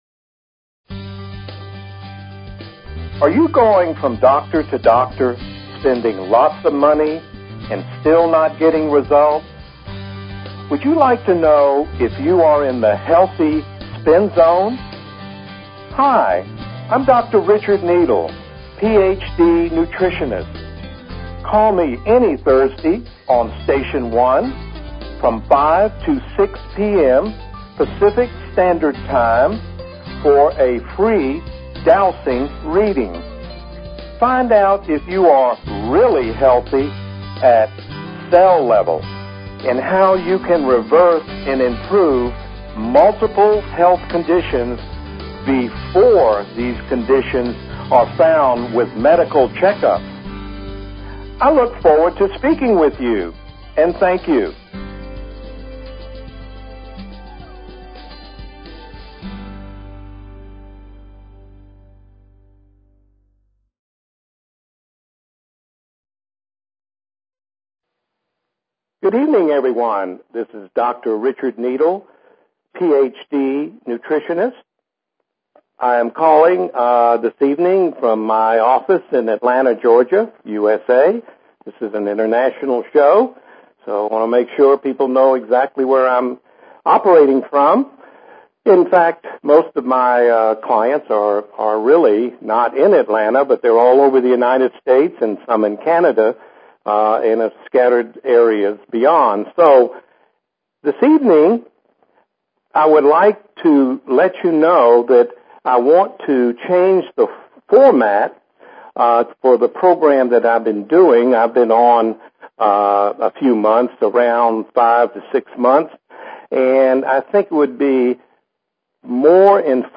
Talk Show Episode, Audio Podcast, Dowsing_for_Health and Courtesy of BBS Radio on , show guests , about , categorized as